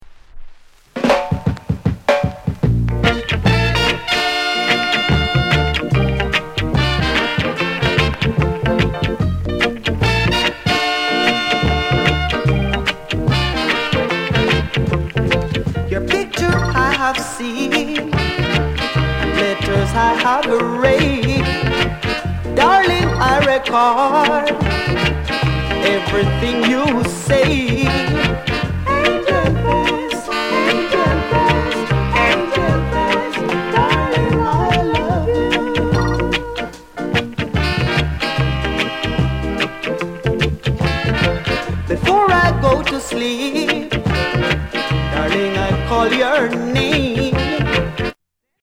vinyl is cloudy ������ NICE VOCAL